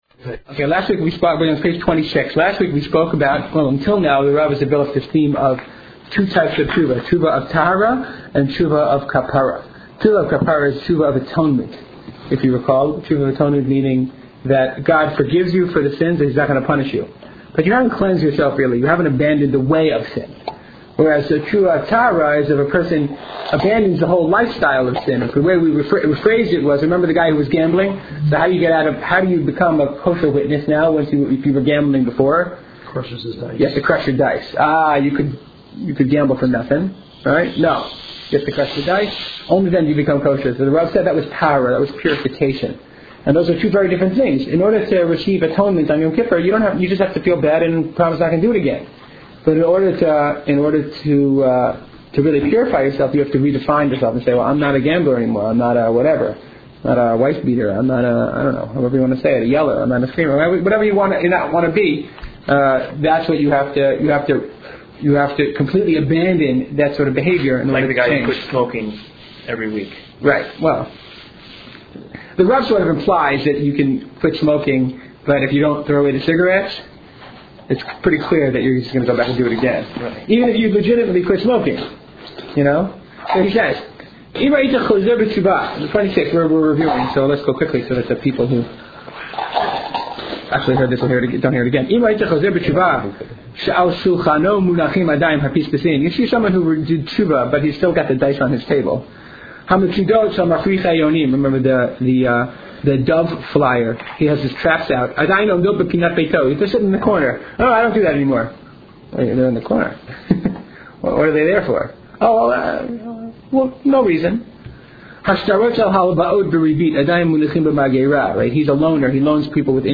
Audio file Al Hateshuvah: Preparing for Rosh Hashanah - A Series from Yad Binyamin This is part of a series of shiurim given to the women of Yad Binyamin, Israel in the Tahalich Women's Learning Program in Yad Binyamin during Elul 5768.